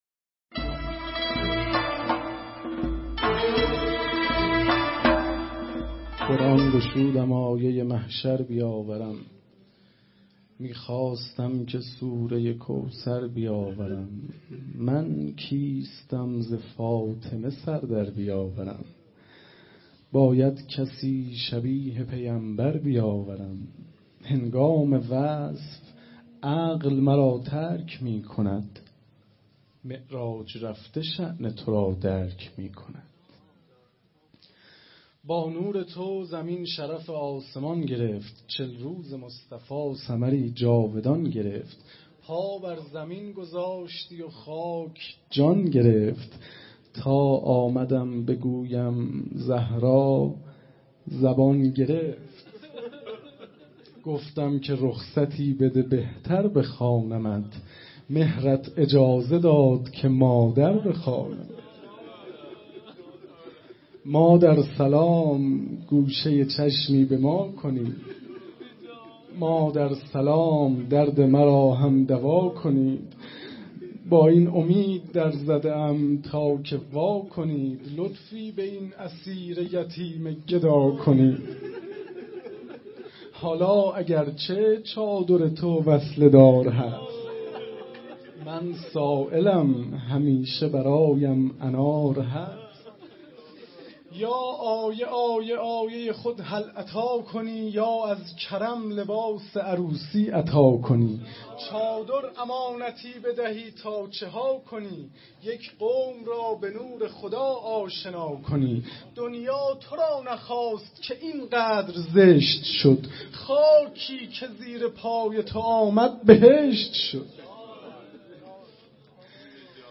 شعر خوانی
محفل عزای فاطمیه اول 1394